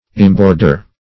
Search Result for " imborder" : The Collaborative International Dictionary of English v.0.48: Imborder \Im*bor"der\, v. t. [imp.
imborder.mp3